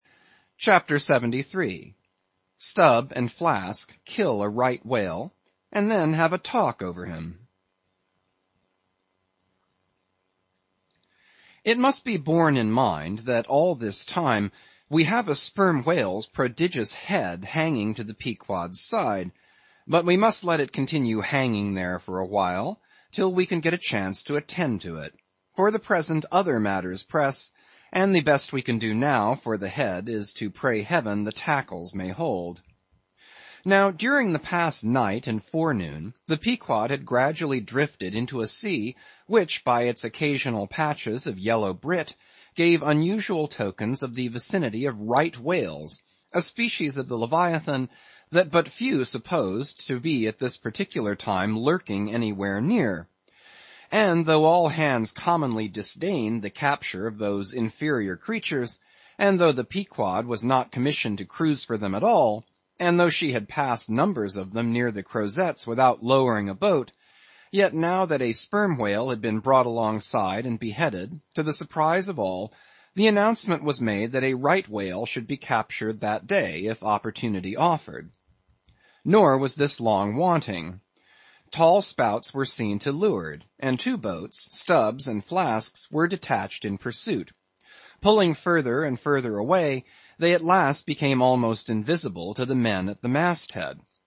英语听书《白鲸记》第660期 听力文件下载—在线英语听力室